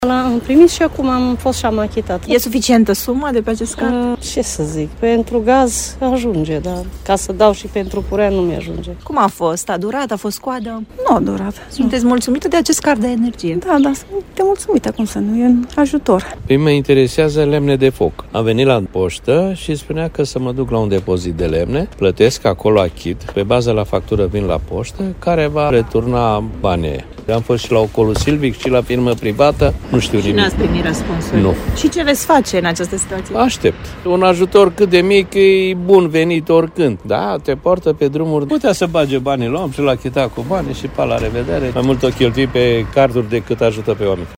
a vorbit cu câţiva dintre cei care au folosit, deja, această modalitate de plată